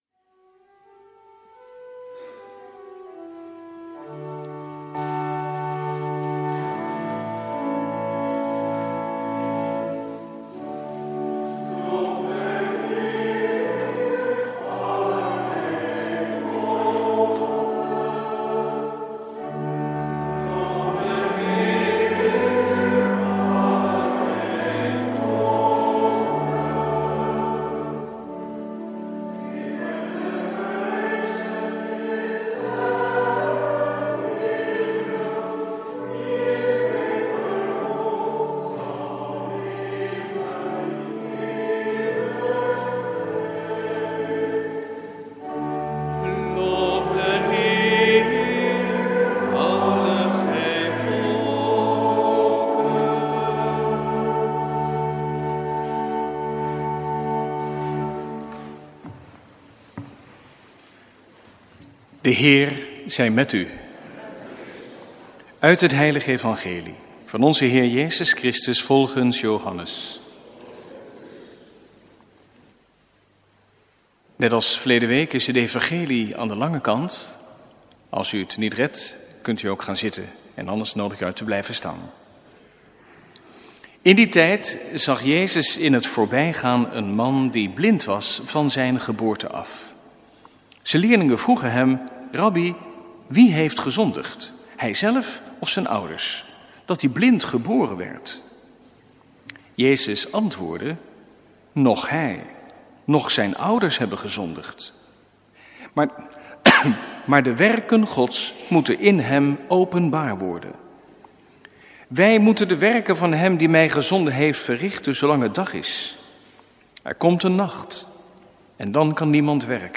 Preek 4e zondag Veertigdagentijd (Laetare), jaar A, 25/26 maart 2017 | Hagenpreken